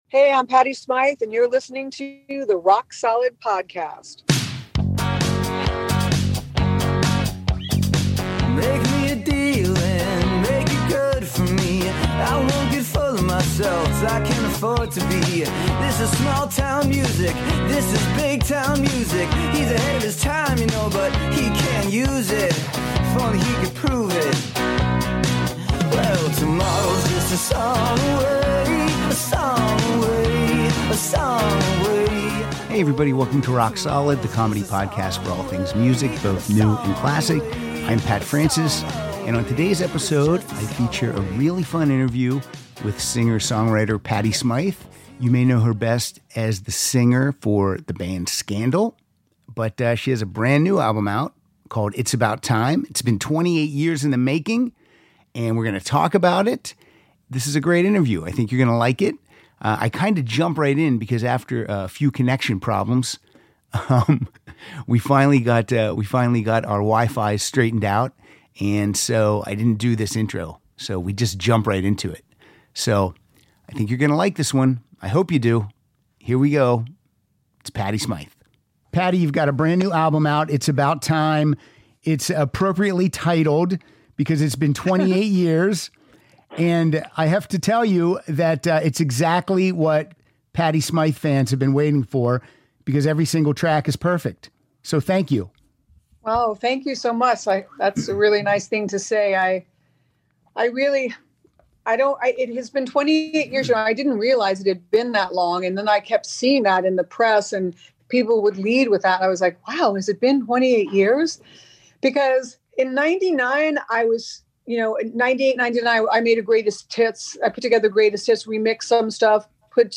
welcomes singer Patty Smyth to the show